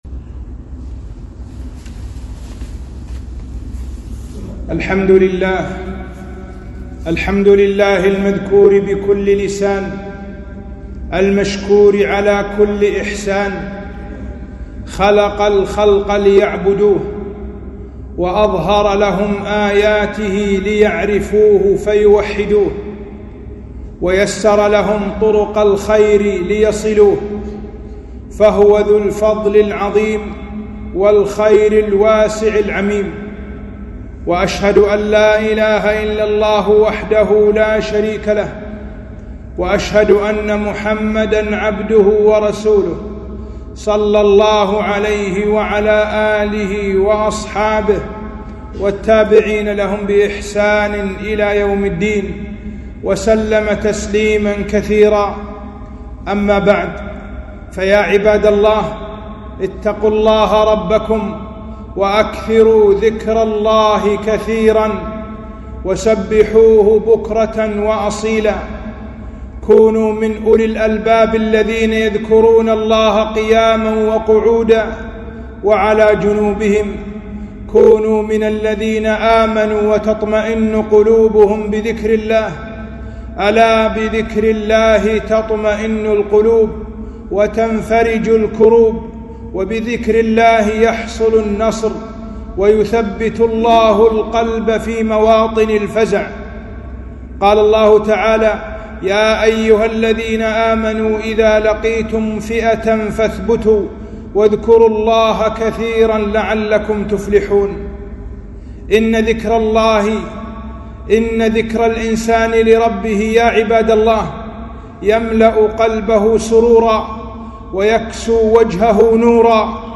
خطبة - فضل الذكر وأنواعه